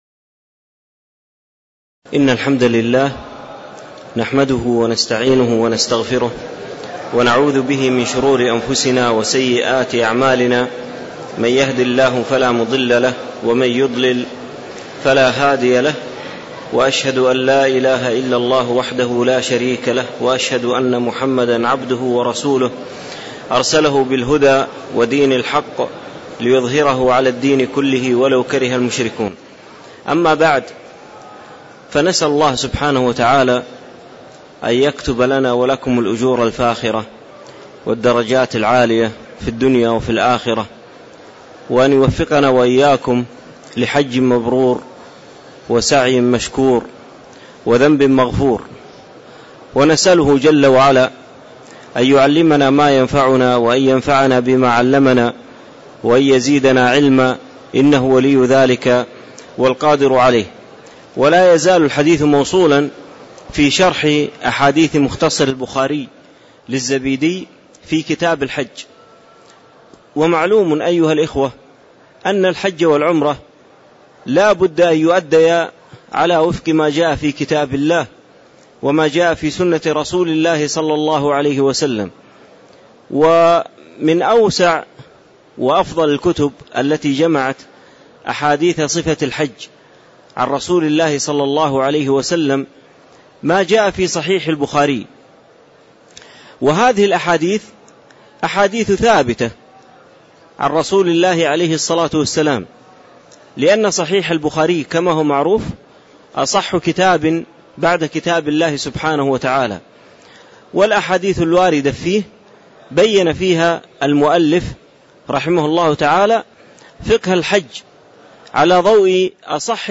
تاريخ النشر ١٨ ذو القعدة ١٤٣٧ هـ المكان: المسجد النبوي الشيخ